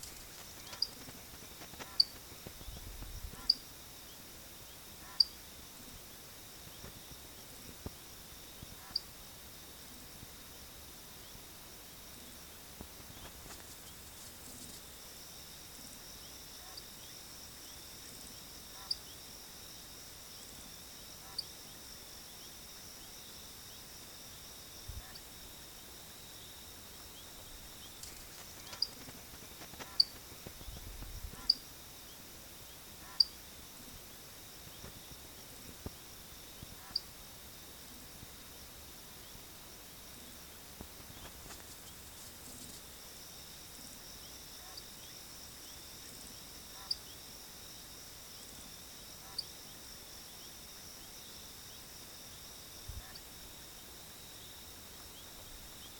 Atajacaminos Tijera (Hydropsalis torquata)
Nombre en inglés: Scissor-tailed Nightjar
Condición: Silvestre
Certeza: Observada, Vocalización Grabada